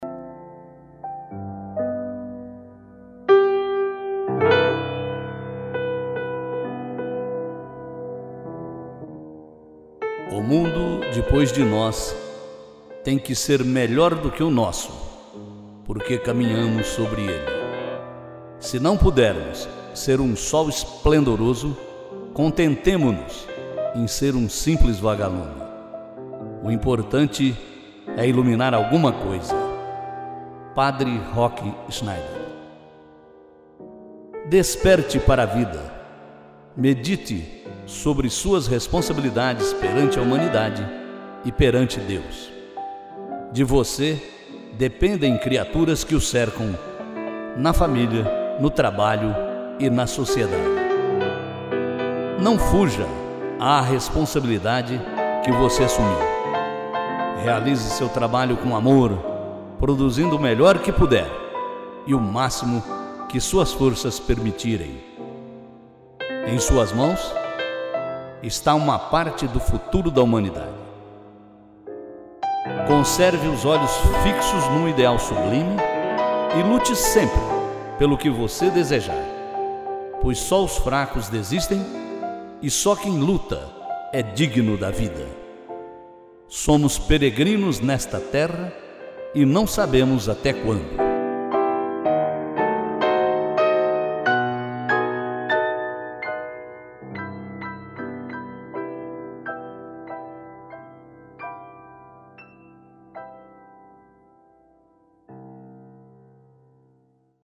Uma mensagem ao amigo - interpretação texto